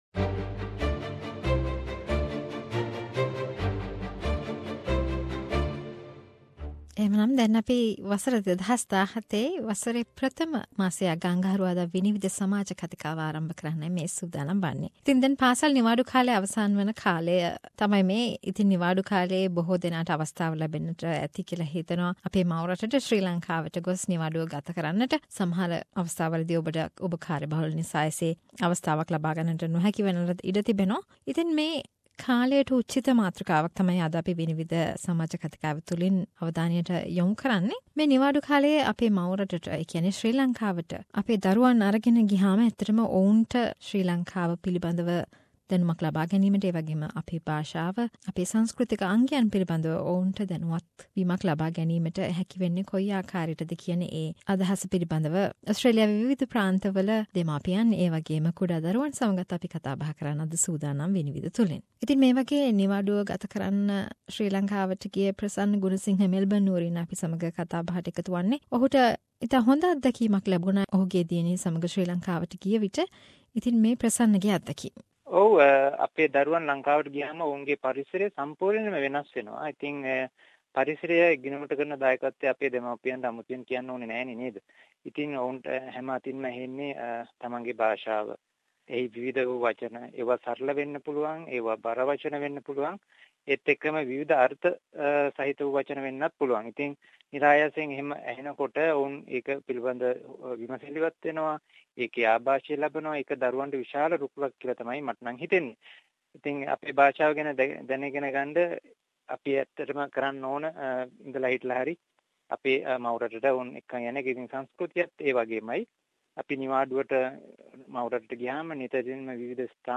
Vinivida January discussion on kids and school holidays